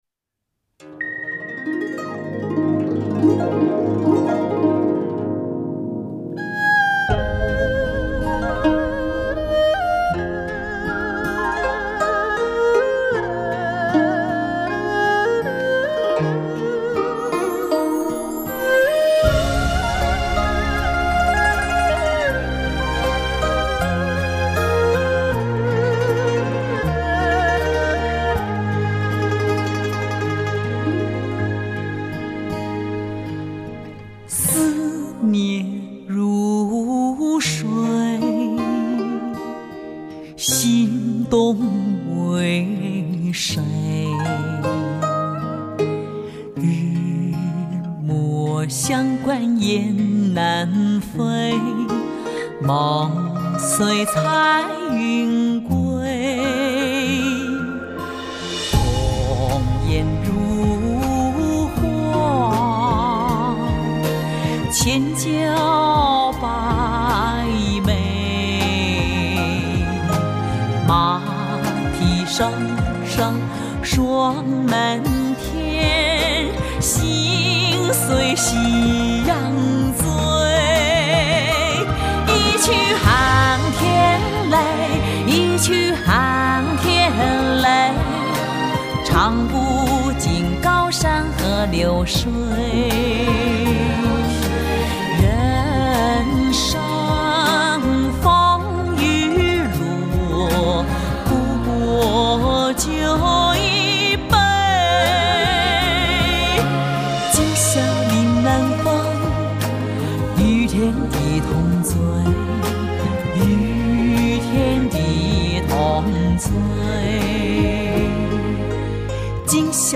温暖醉厚的女中音倾情演绎，乐坛不可多得的高品质音乐。
低沉、醉厚、婉约的质感，丝丝如扣，动人心弦，像天籁之声温暖心怀。
专辑以广东音乐元素创作，具有浓郁的岭南文化特征和人文气质。
演唱委婉抒情、声线醇厚而温暖、宽阔而细腻。